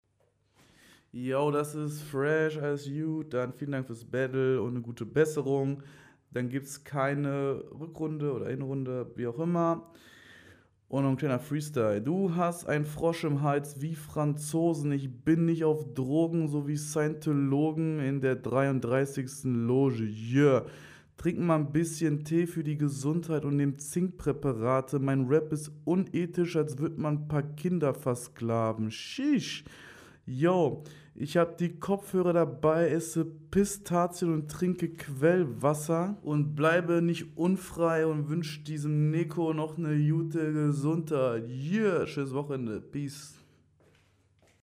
Freestyle ist insane gut, weiter so!